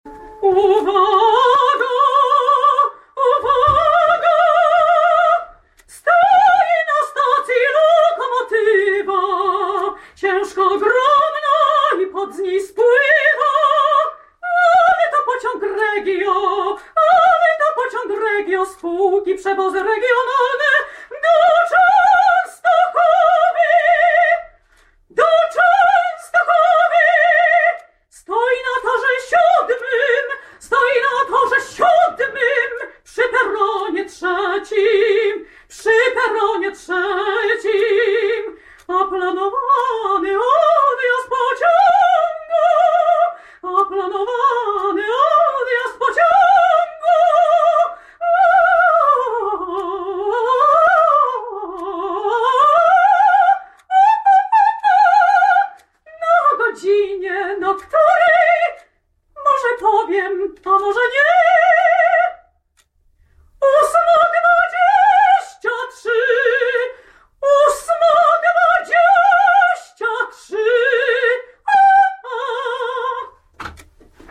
…w dniu dzisiejszym [wczorajszym: 13 X 2011] odbyła się premiera „Opery Dworcowej” Marcina Polaka w przestrzeni Dworca Kolejowego Łódź-Fabryczna.
sopran
dworzec Łódź opera PKP